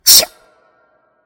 ZUBAT.mp3